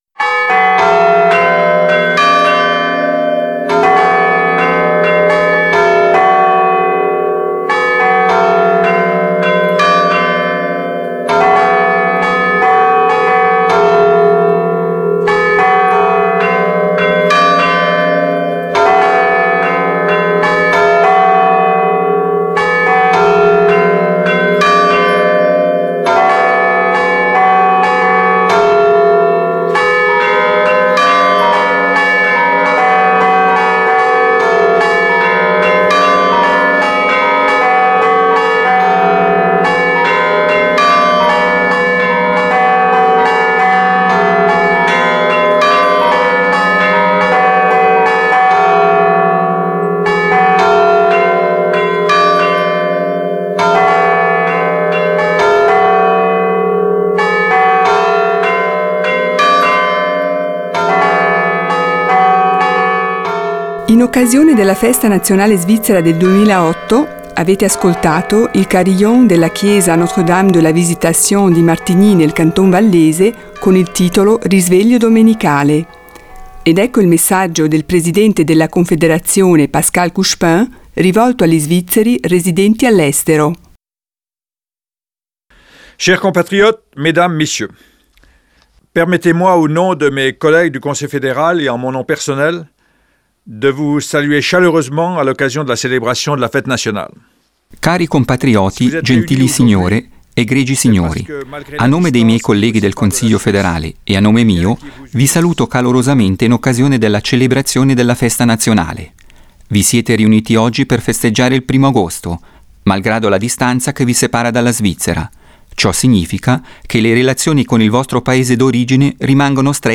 Allocuzione del presidente della Confederazione Pascal Couchepin per gli Svizzeri all'estero.